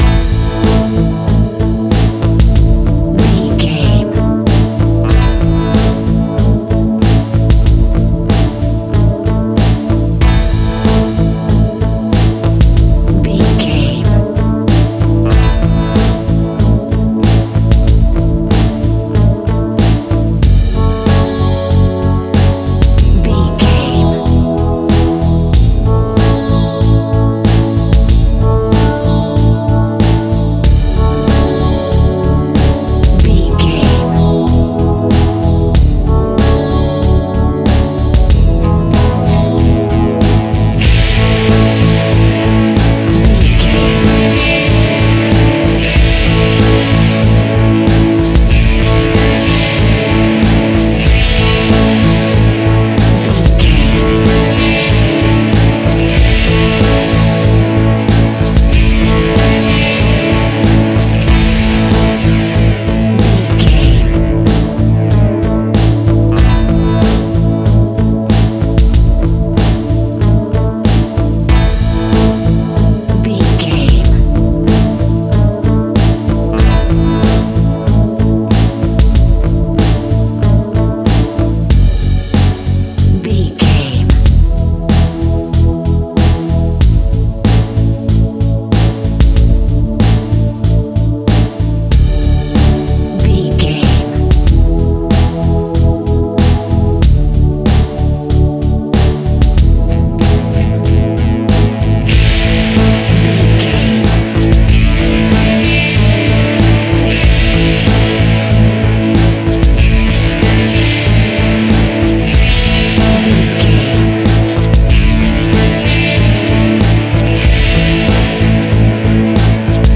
80s Pop Rock Ballad.
Ionian/Major
emotional
soft rock
electric guitar
bass guitar
drums
synthesizers